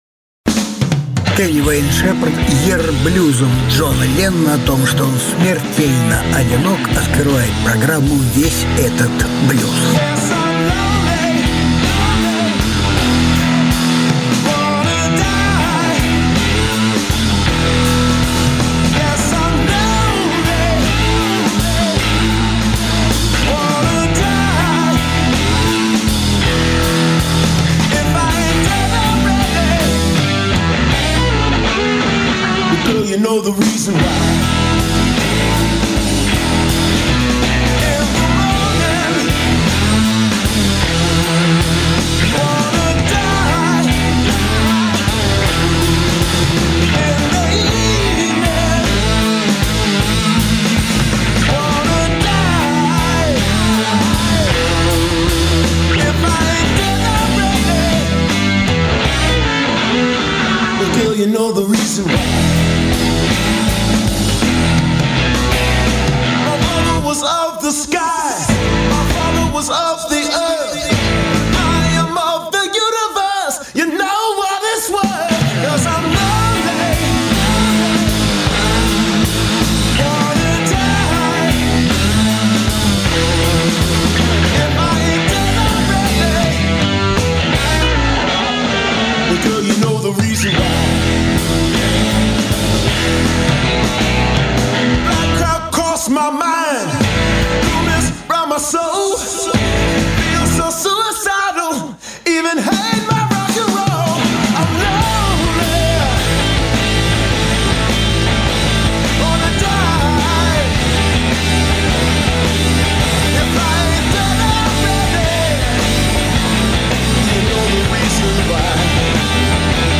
Ronnie Earl - блюзовый гитарист.
Жанр: Блюзы